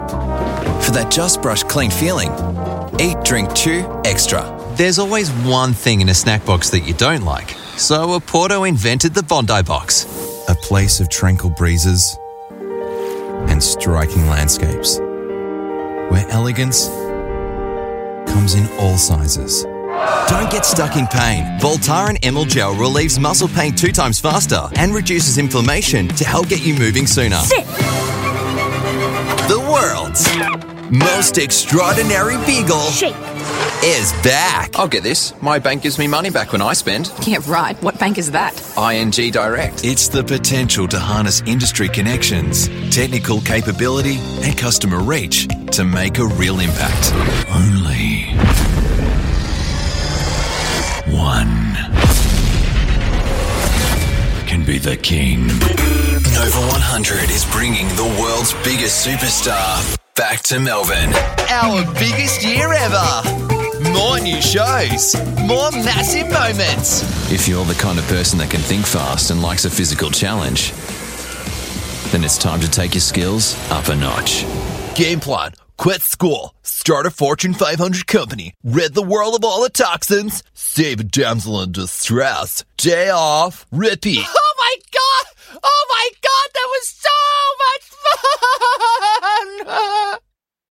Self aware, dry comedy, Gritty, Smooth, deep, authoritative, classy, announcer, elegant, refined, powerful, military, humorous, dry, funny, sarcastic, witty, somber, poetic, storyteller, friendly, informative, ...